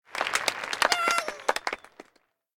KART_Applause_4.ogg